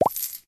coins_collect.ogg